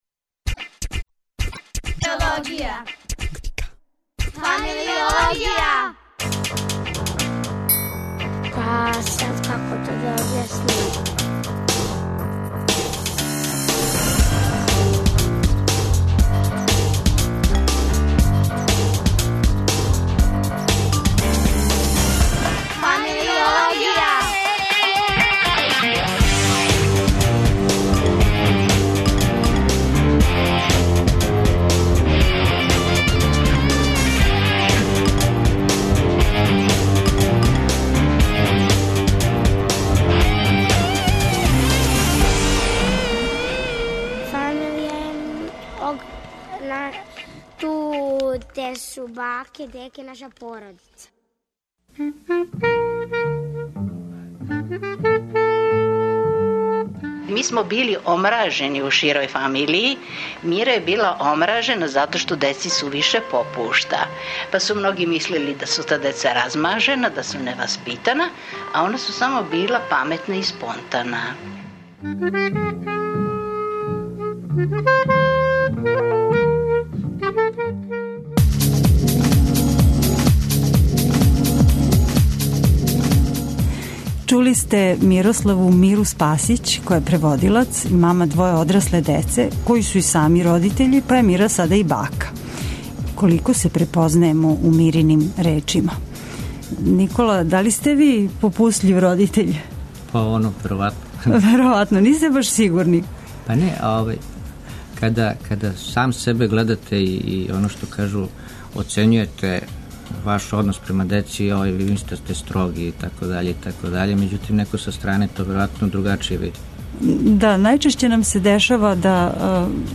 Радио Београд 1, 13.00